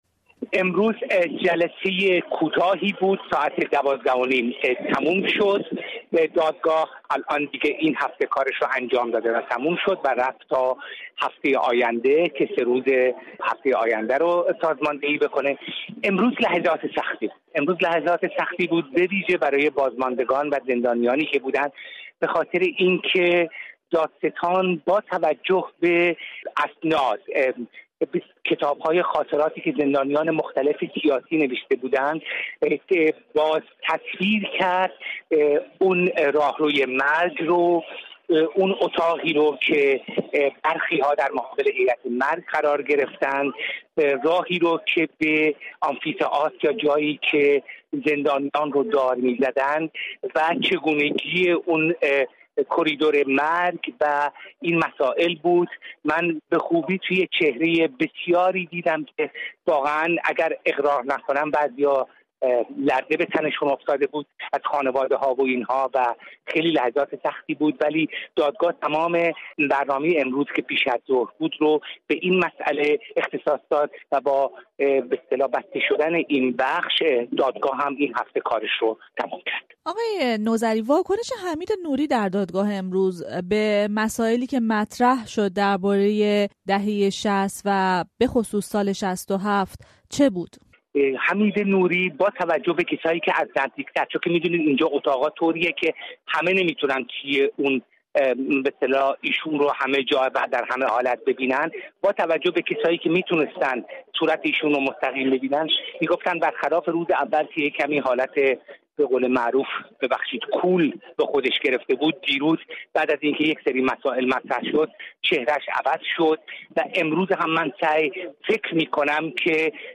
در مصاحبه با رادیو فردا